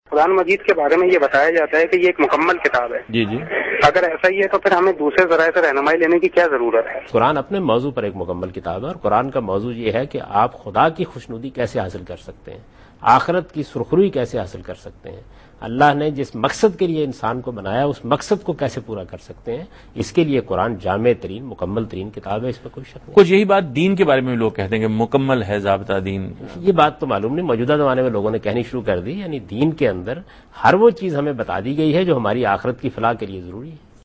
Category: TV Programs / Dunya News / Deen-o-Daanish /
Javed Ahmad Ghamidi answers a question regarding "Quran and Guidance " in program Deen o Daanish on Dunya News.